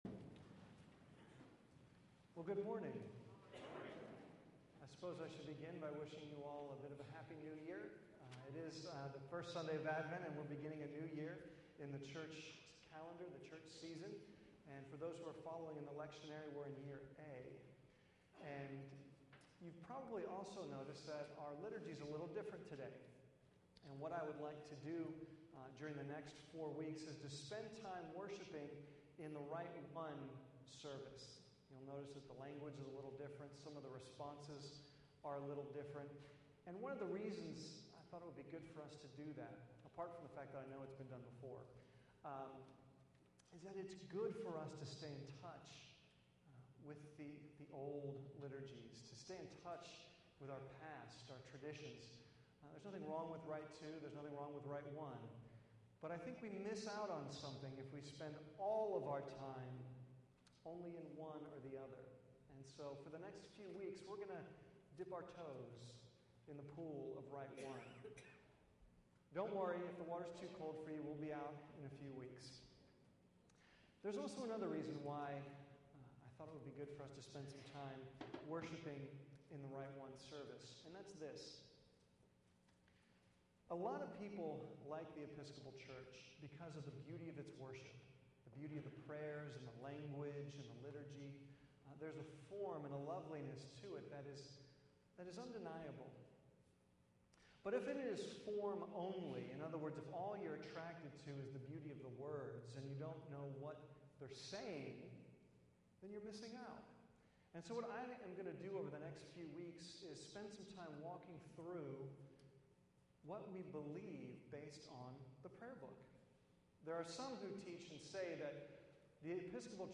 There is a direct connection between what we do and say in worship (liturgy) and what we believe (doctrine). This sermon focuses on the Collect for the First Sunday of Advent, which is strongly characterized by themes of light and dark.